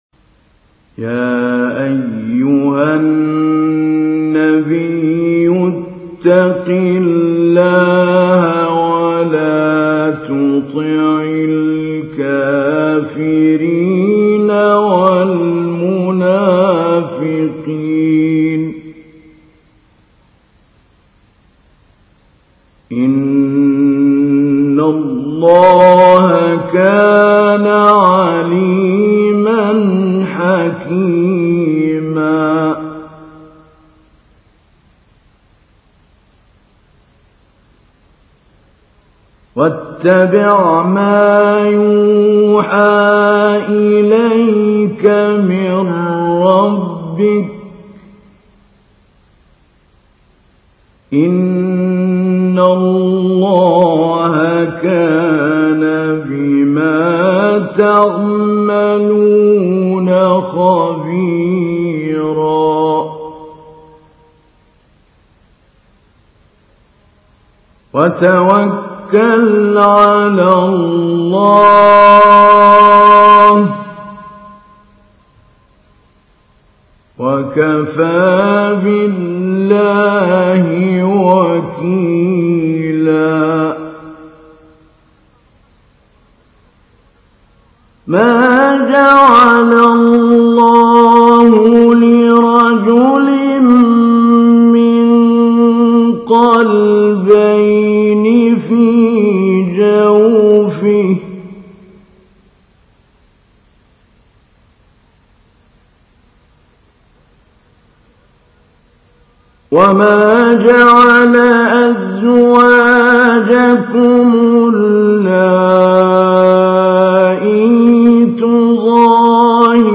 تحميل : 33. سورة الأحزاب / القارئ محمود علي البنا / القرآن الكريم / موقع يا حسين